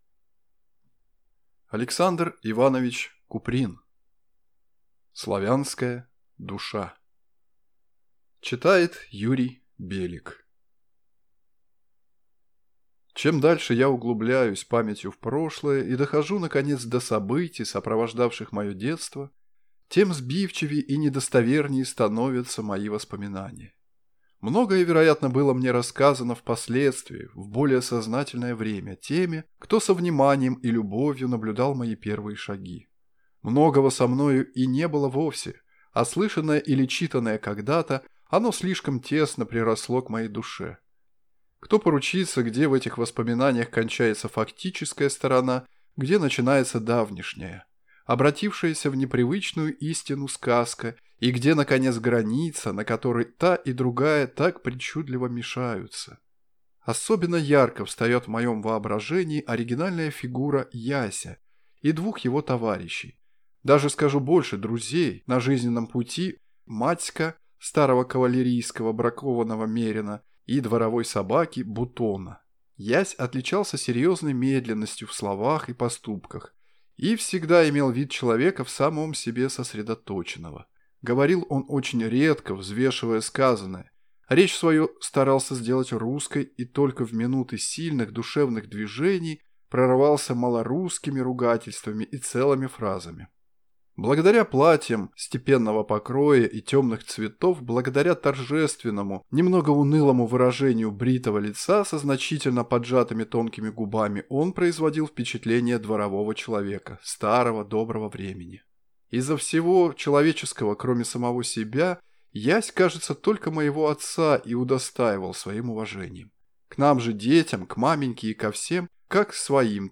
Аудиокнига Славянская душа | Библиотека аудиокниг